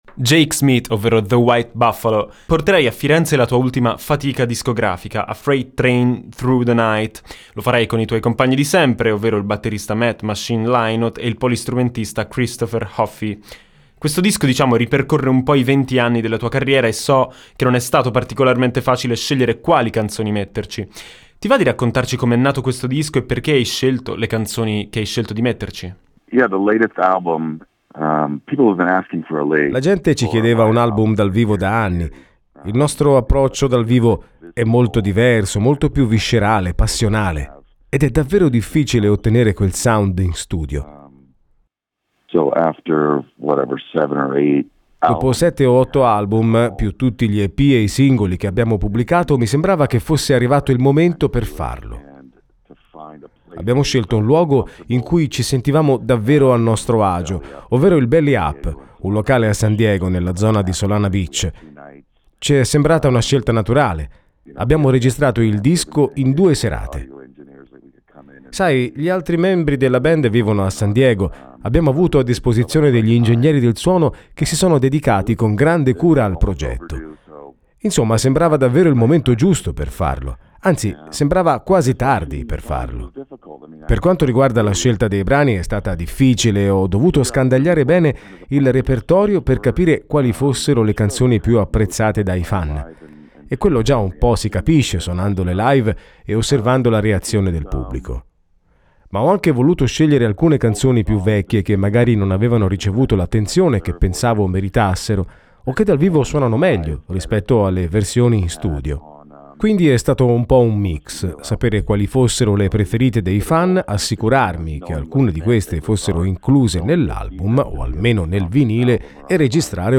WBinterview.mp3